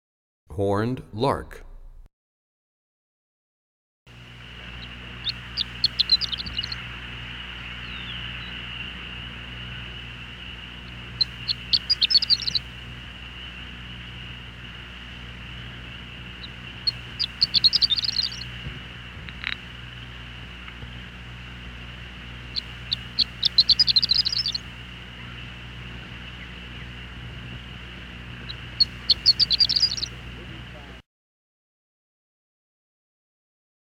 44 Horned Lark.mp3